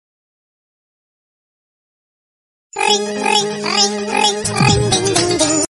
Download Cute Sms Ringtone.
• Soft and Sweet Notification Sound
• Short and Clear Sound